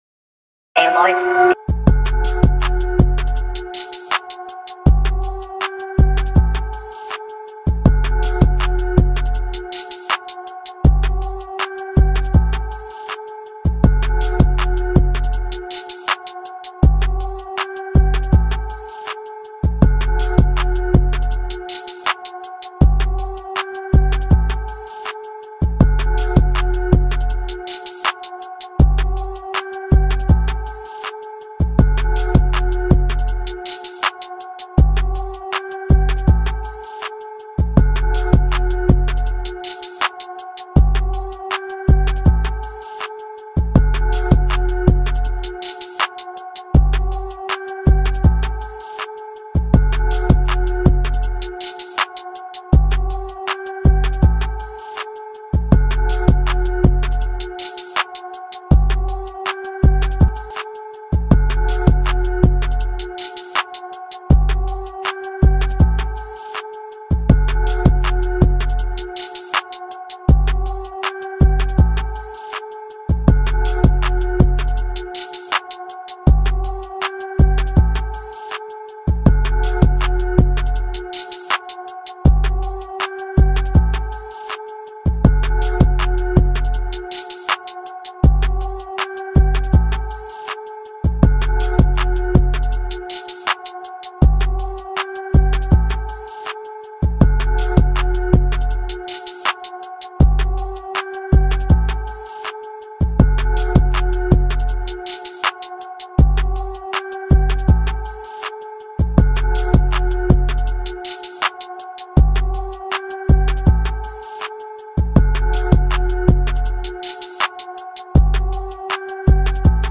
instrumental beat remake